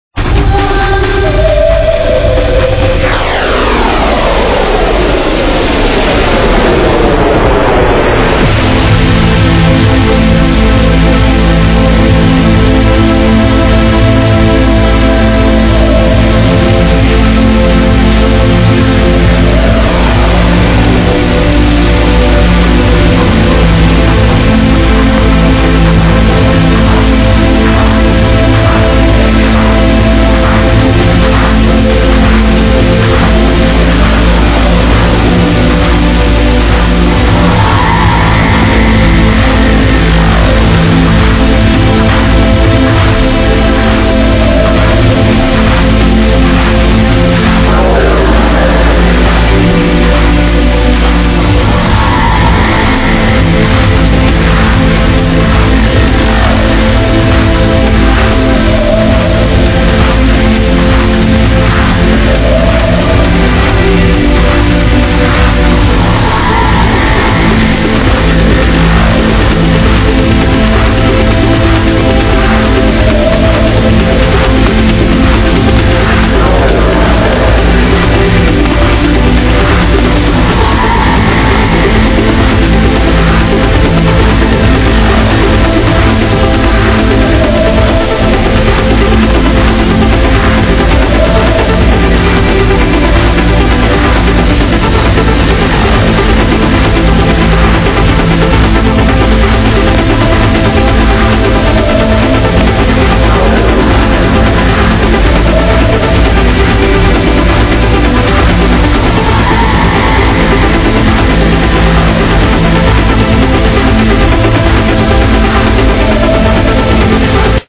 dunno but sounds sweet